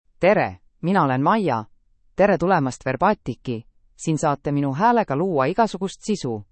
MayaFemale Estonian AI voice
Maya is a female AI voice for Estonian (Estonia).
Voice sample
Listen to Maya's female Estonian voice.
Female
Maya delivers clear pronunciation with authentic Estonia Estonian intonation, making your content sound professionally produced.